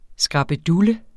Udtale [ sgʁɑbəˈdulə ]